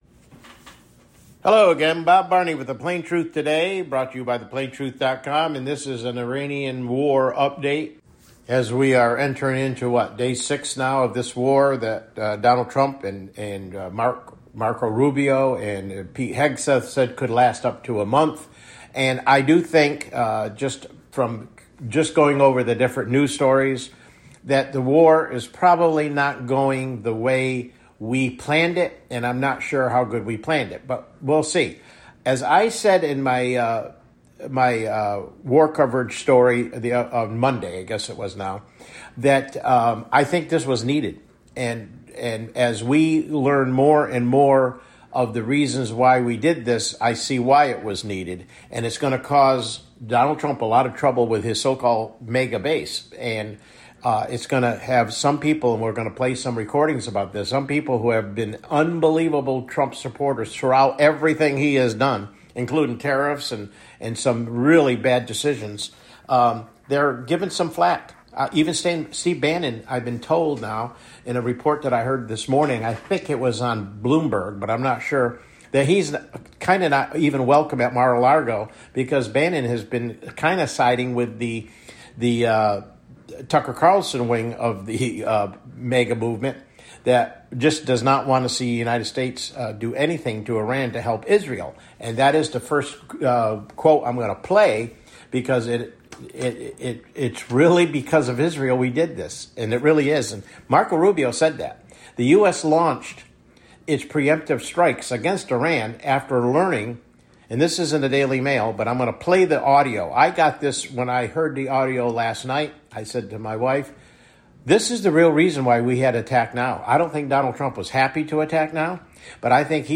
CLICK HERE TO LISTEN TO THE PLAIN TRUTH TODAY MIDDAY BROADCAST